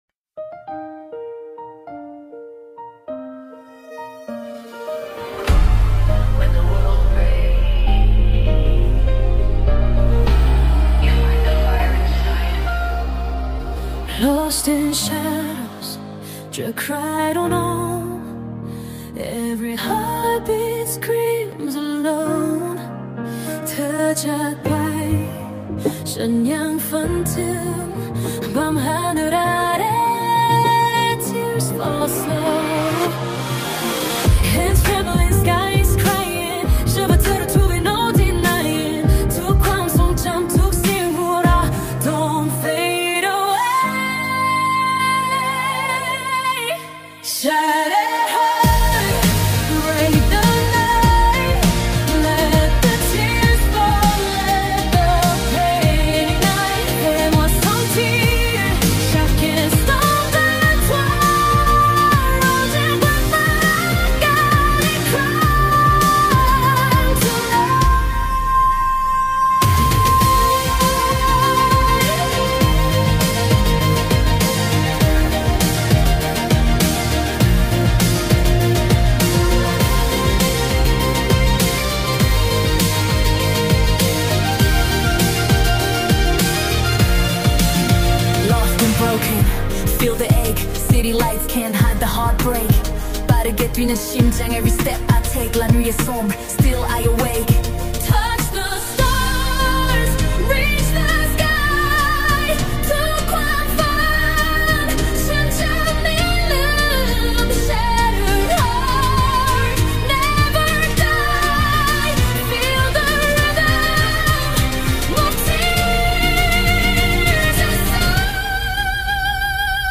KpopBallad
SadBallad
EmotionalVocal
and tears in a soul-stirring ballad.